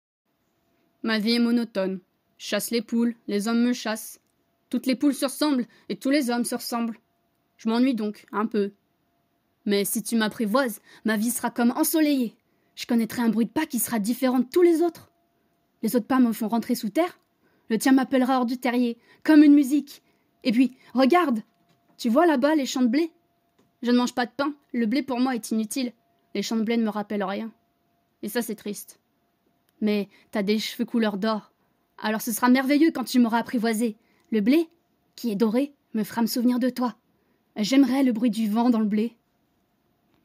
Voix Garçon - Le Renard
8 - 37 ans